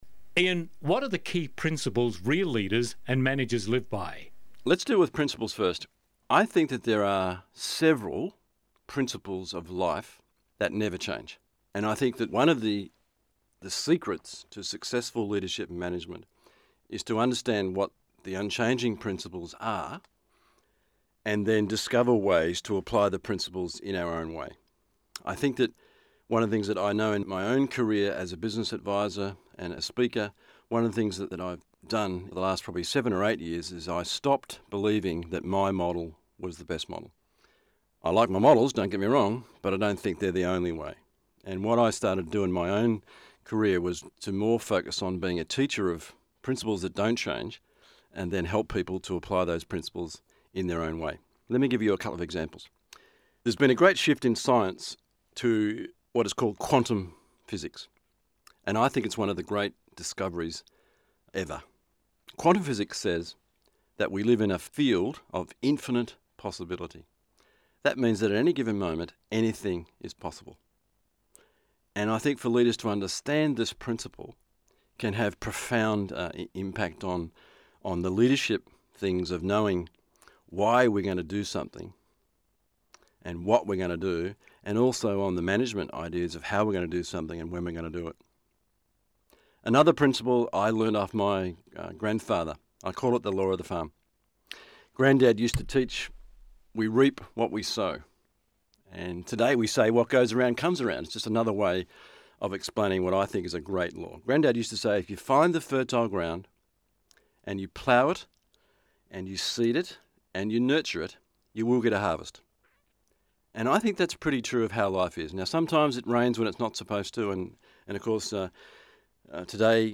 Business Audio Seminar